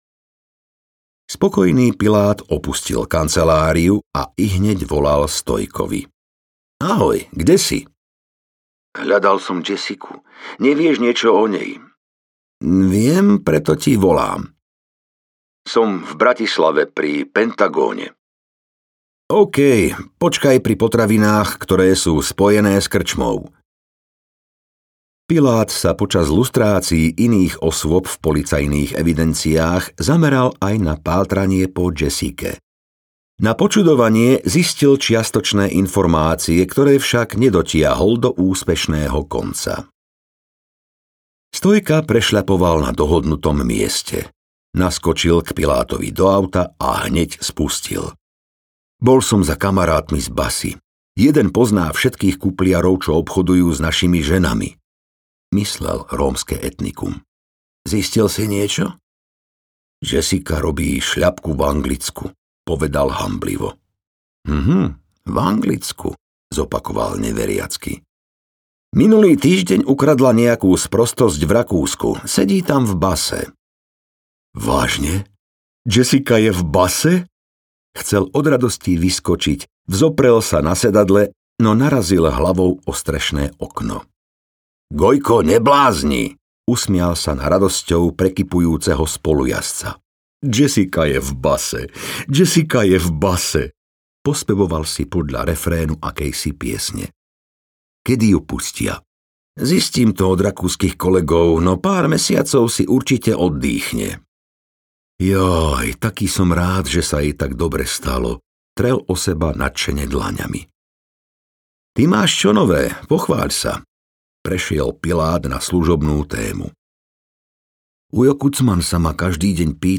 Zákony zločinu audiokniha
Ukázka z knihy